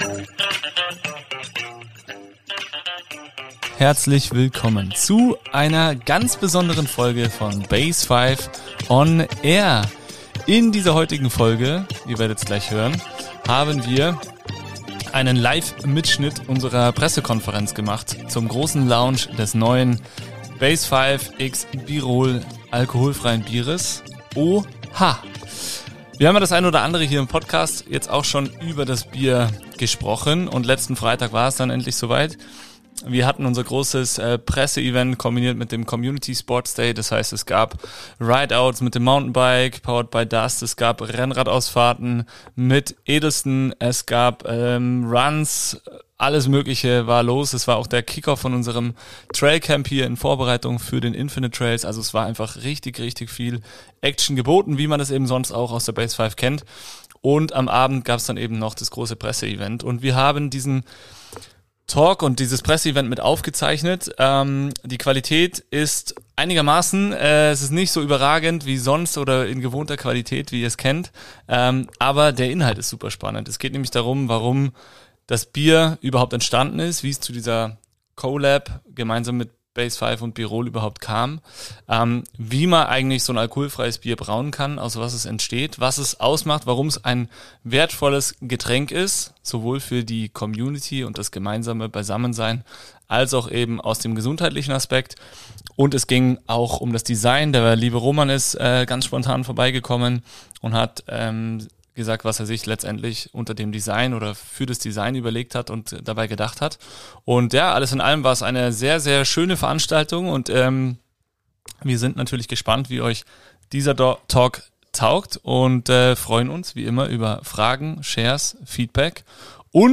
In dieser Spezialfolge feiern wir gemeinsam mit Bierol den internationalen Tag des Bieres und stellen unser neues, alkoholfreies Bier „OHA“ vor. Live aus der BASEFIVE sprechen wir über den Geschmack, die Idee, das Design und die Entwicklung dieses besonderen Pale Ales.